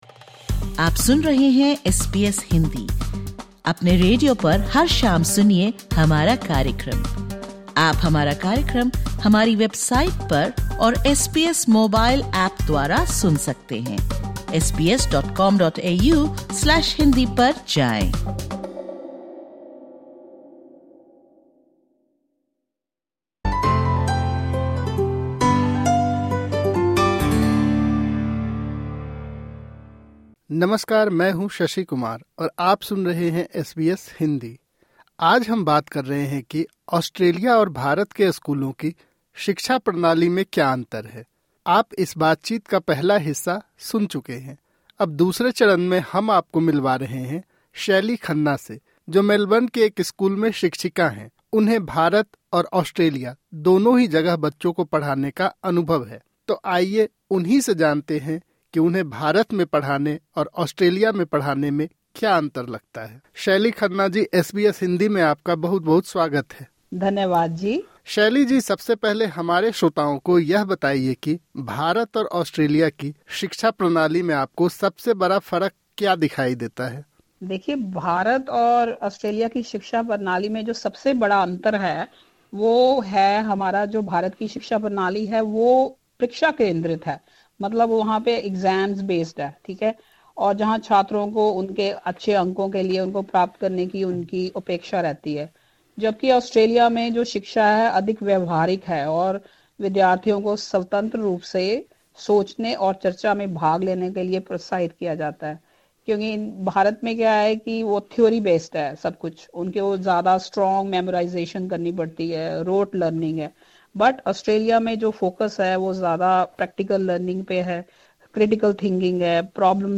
भारत और ऑस्ट्रेलिया की शिक्षा प्रणालियों में बहुत फर्क है। सीखने का लक्ष्य एक ही है, लेकिन तरीके थोड़े अलग हैं। इस बातचीत में